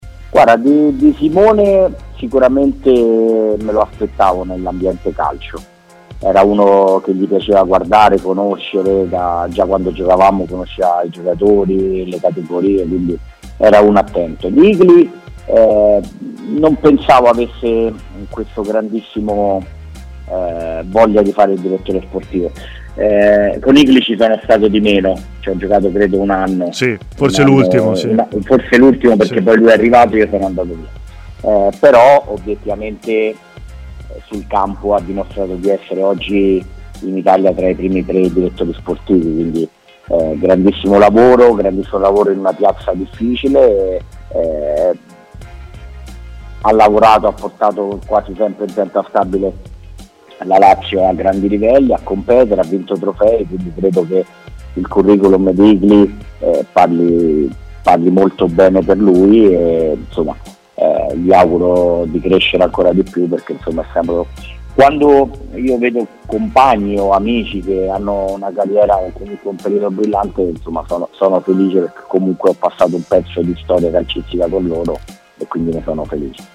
L'allenatore, ex giocatore della Lazio, ha parlato del percorso dei suoi ex compagni Inzaghi e Tare ai microfoni di TMW Radio